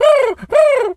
pigeon_call_angry_03.wav